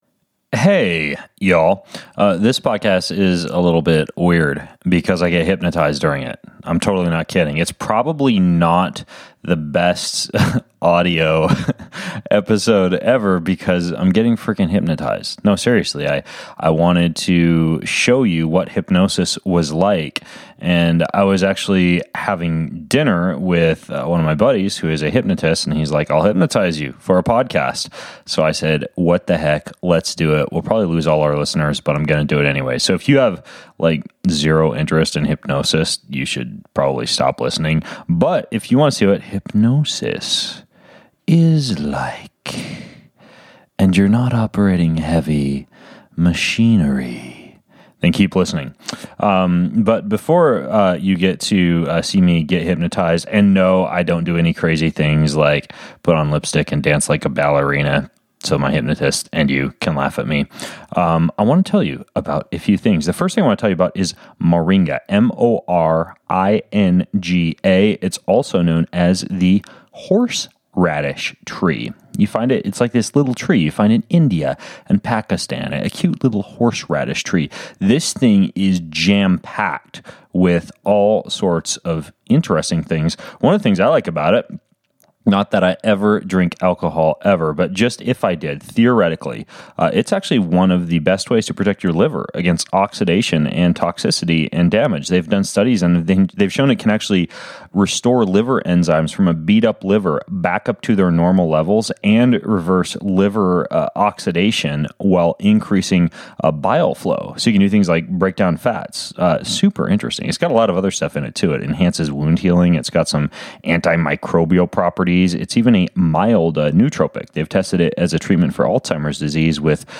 In Which Ben Greenfield Is Hypnotized Live On A Podcast (And 3 Embarrassing Personal Admissions From Ben).
Warning: I get hypnotized in this podcast and it may make you sleepy when you listen, so please don’t do